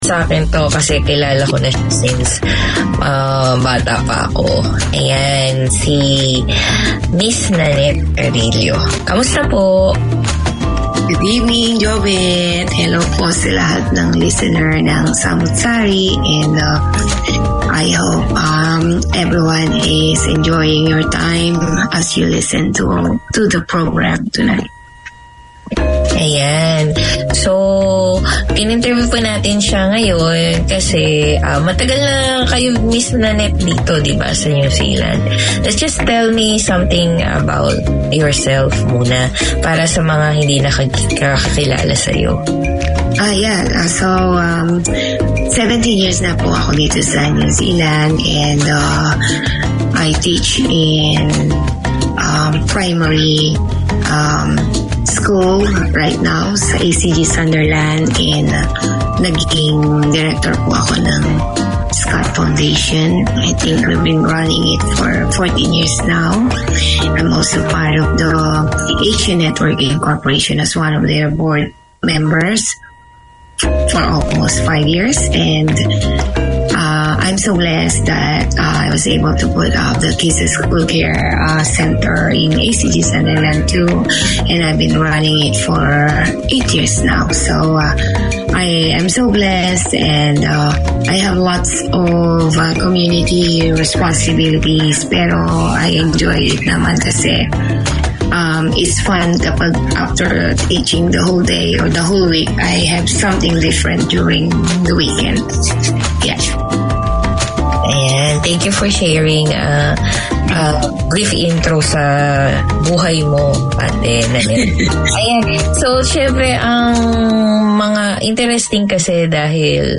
Konkani is the language of Goa, Mangalore, Karwar from South of Maharshtra to north of Kerala and is only spoken, rather than written, so radio is the perfect place to keep the language alive in the Kiwi Konkani community. Your hosts, the Good Guys, play a rich selection of old and contemporary Konkani music, talk with local community personalities, present short radio plays, connect with community events and promote the culture and traditions.